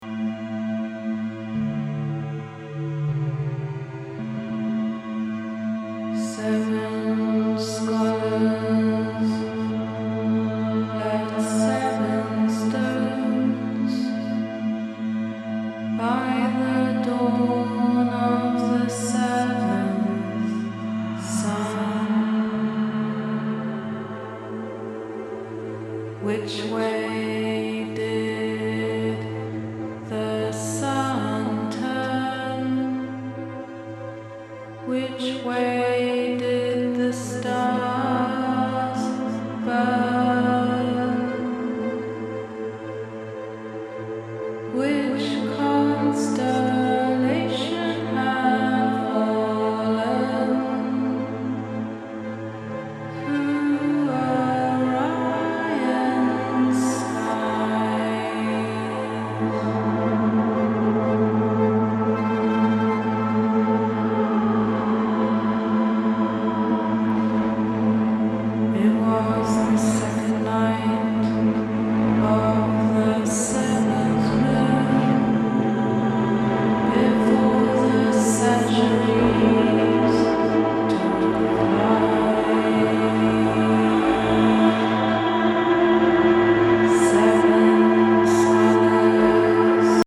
poet / musician collective